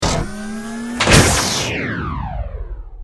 battlesuit_largelaser.ogg